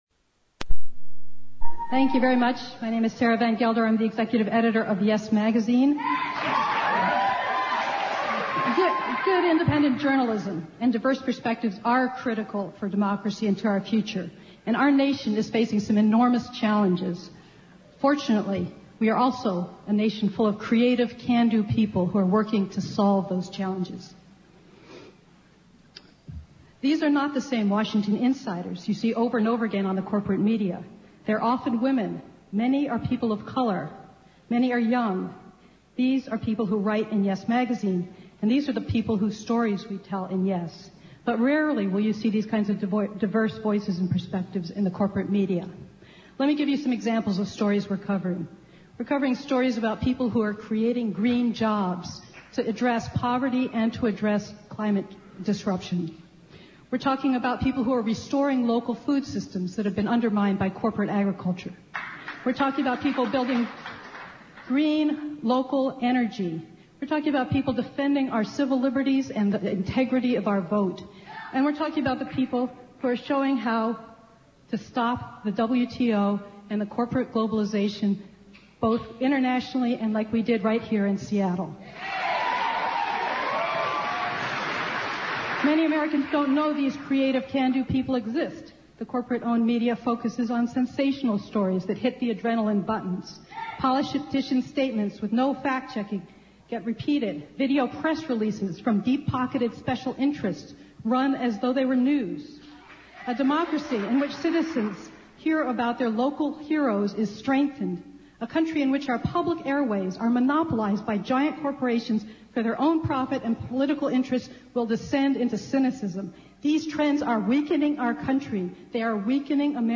§FCC holds Seattle public hearings on deregulation
Two representatives from the activist publication "Yes Magazine" discuss how media consolidation unduly limits access for independent print publications and how this round of deregulation will worsen the problem.